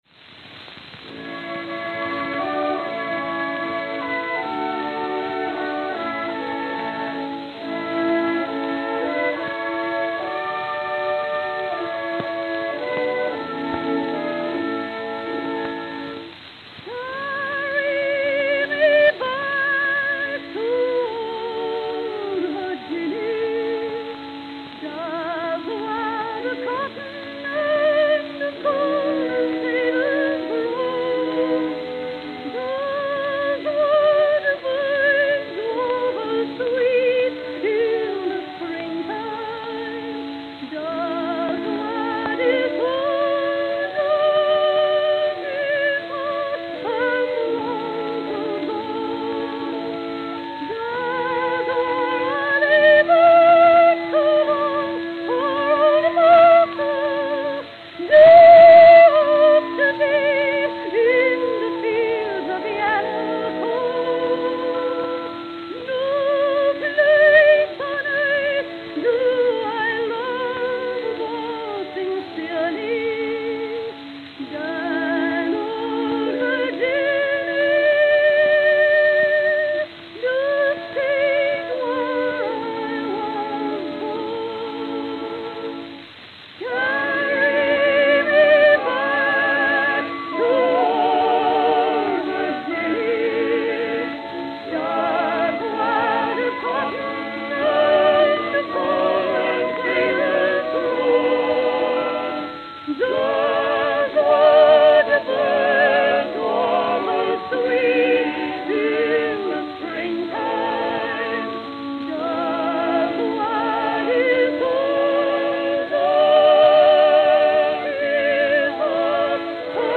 Camden, New Jersey
Note: Worn.